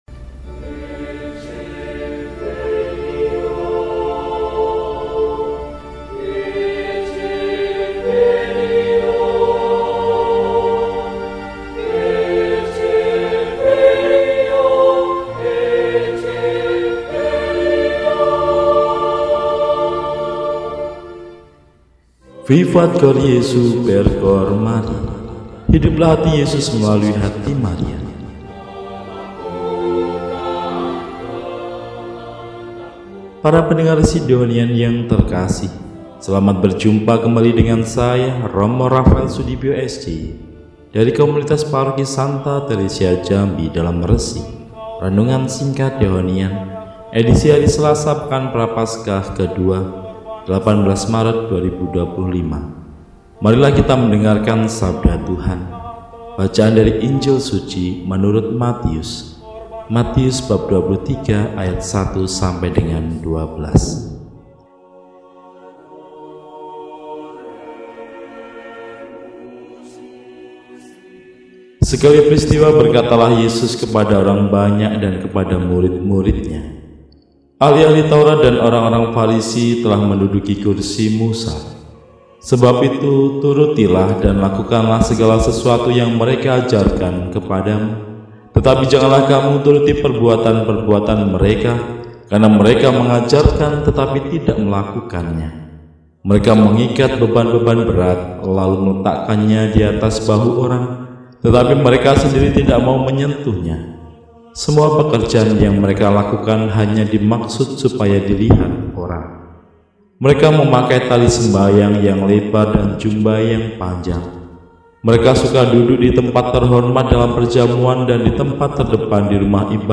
Selasa, 18 Maret 2025 – Hari Biasa Pekan II Prapaskah – RESI (Renungan Singkat) DEHONIAN